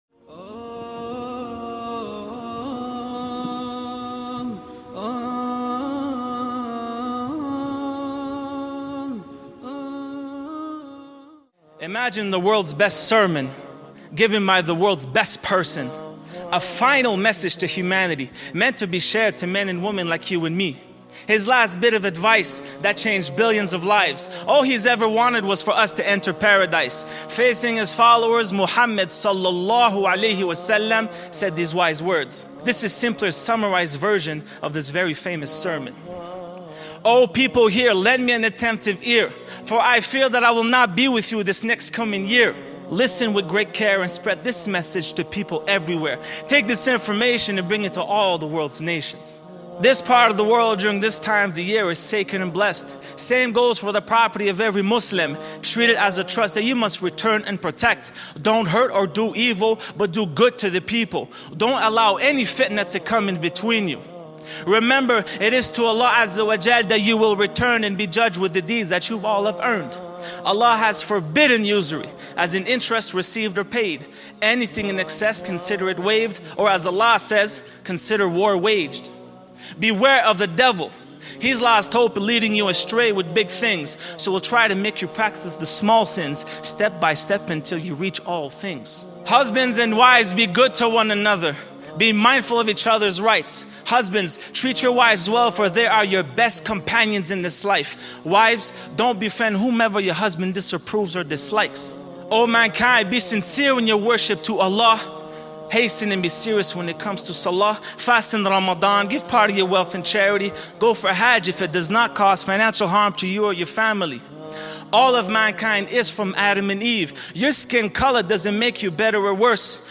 This piece was performed live at the IlmFest Conference in Minneapolis, Minnesota on Saturday, December 1, 2012.